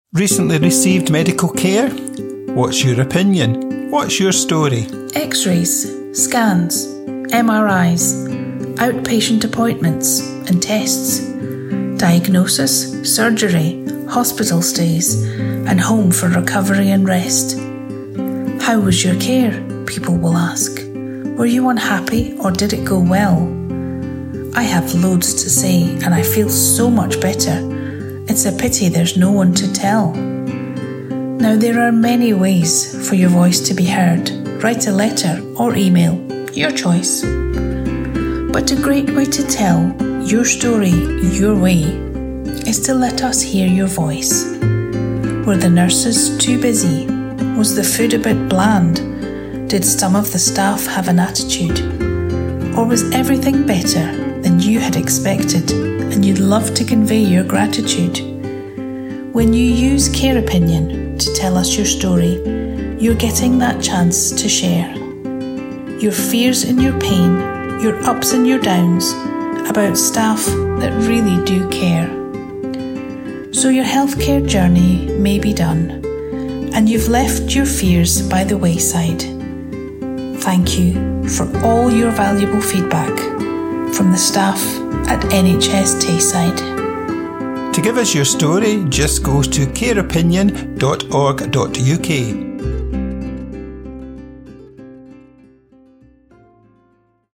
Care Opinion Promo - Mixed.mp3